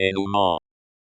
e.nu.mɑ̃/.